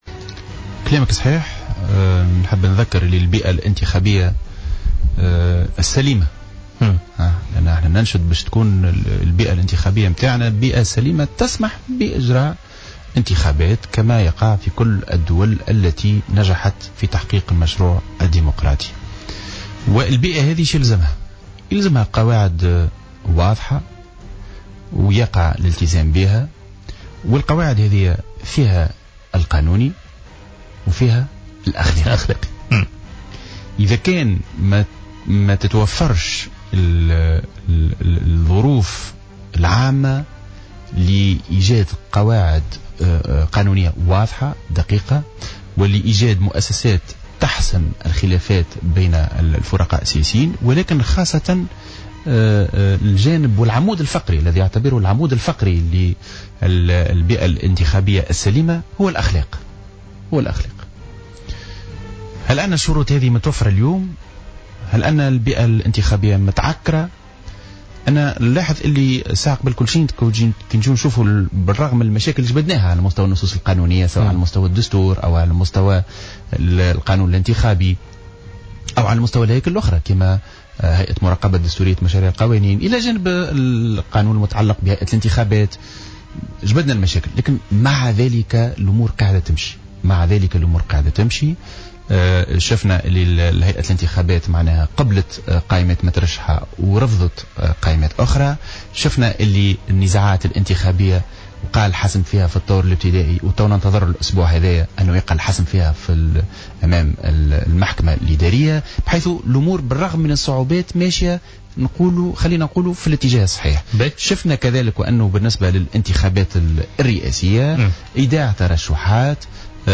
في مداخلة له في برنامج "بوليتيكا" إن الساحة السياسية في تونس تعيش أزمة أخلاق.